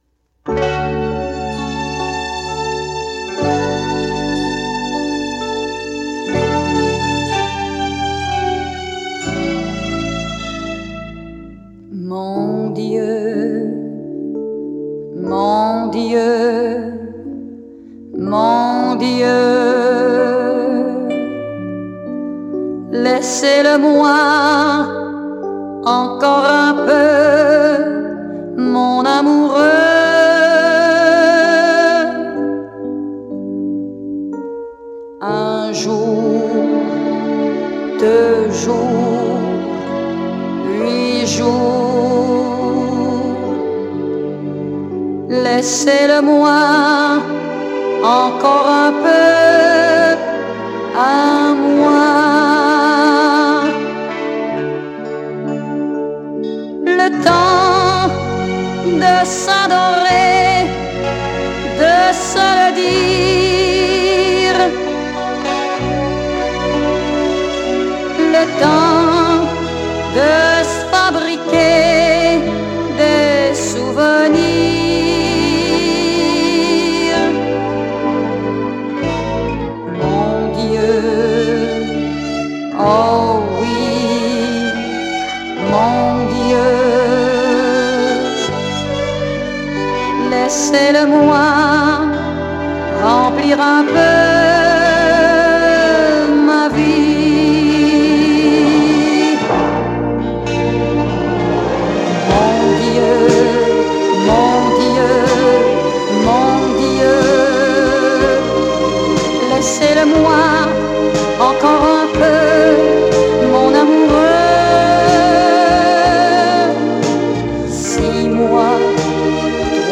Son: stéréo
Enregistrement: Studio St-Charles à Longueuil